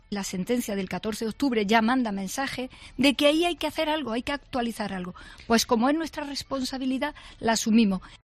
Declaraciones de Carmen Calvo sobre el Código Penal en Cadena Ser